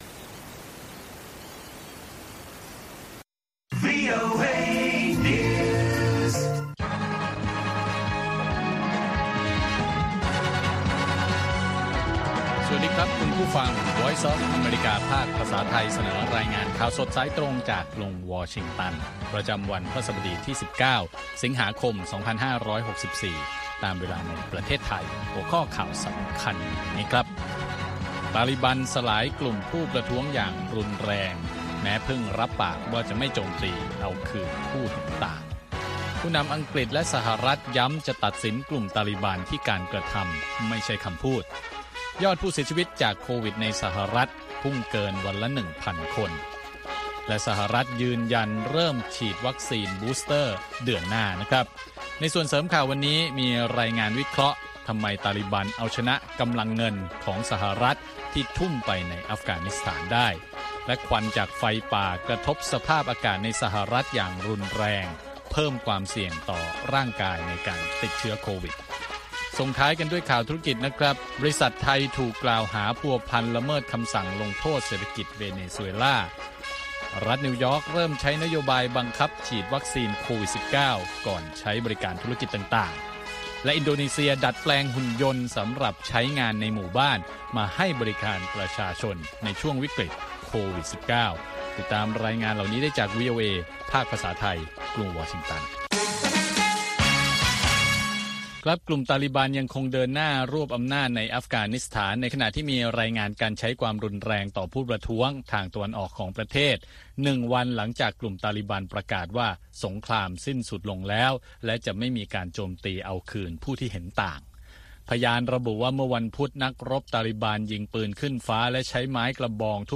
ข่าวสดสายตรงจากวีโอเอ ภาคภาษาไทย 6:30 – 7:00 น. ประจำวันพฤหัสบดีที่ 19 สิงหาคม 2564